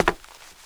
Index of /90_sSampleCDs/AKAI S6000 CD-ROM - Volume 6/Human/FOOTSTEPS_1
HARDWOOD C.WAV